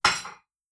Impacts
clamour10.wav